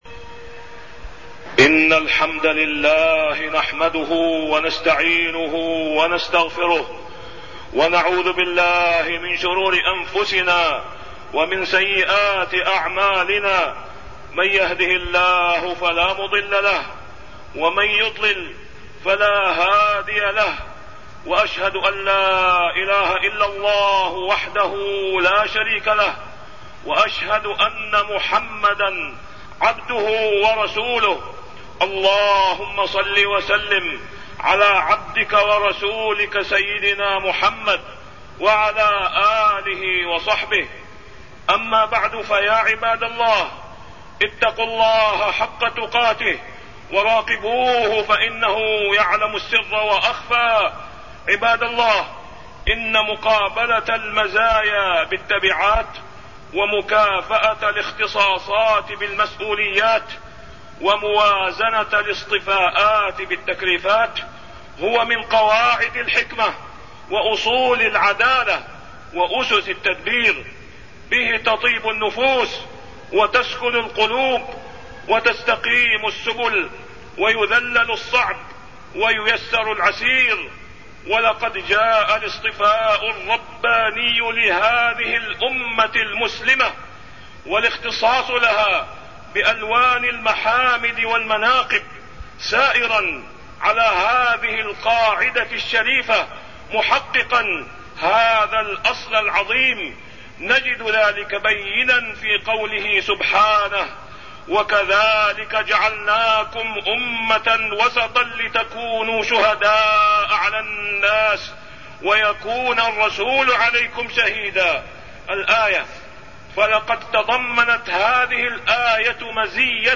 تاريخ النشر ١٩ ربيع الثاني ١٤٢١ هـ المكان: المسجد الحرام الشيخ: فضيلة الشيخ د. أسامة بن عبدالله خياط فضيلة الشيخ د. أسامة بن عبدالله خياط وسطية هذه الأمة